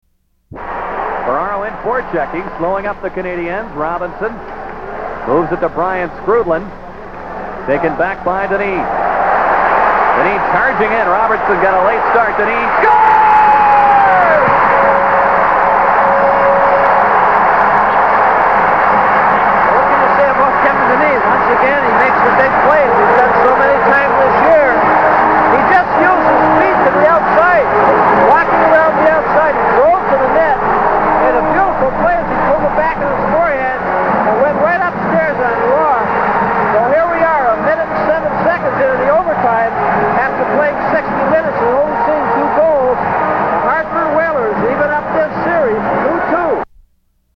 Play-by-Play